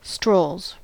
Ääntäminen
Ääntäminen US Haettu sana löytyi näillä lähdekielillä: englanti Käännöksiä ei löytynyt valitulle kohdekielelle. Strolls on sanan stroll monikko.